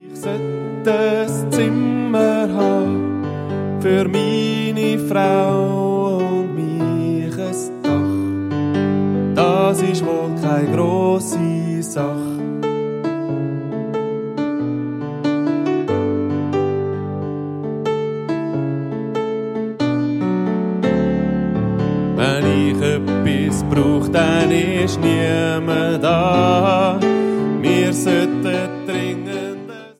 Ein Klassiker unter den Weihnachts-CDs